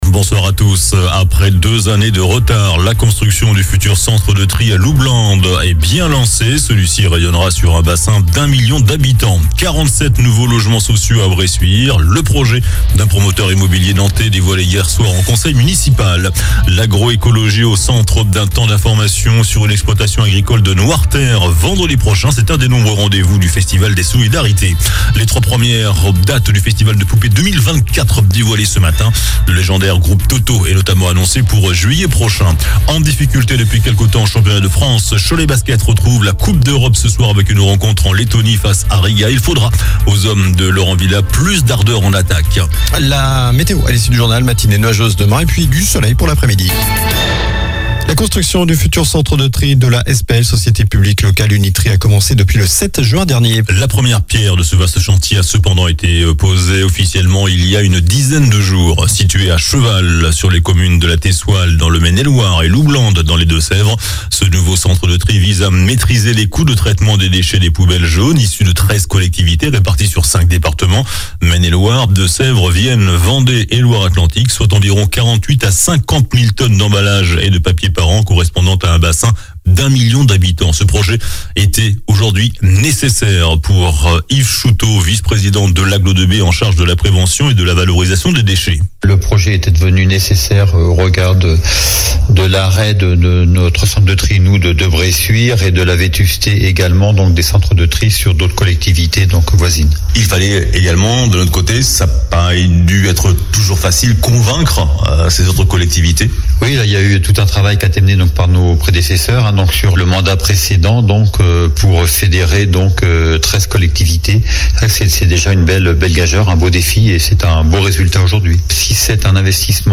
JOURNAL DU MARDI 22 NOVEMBRE ( SOIR )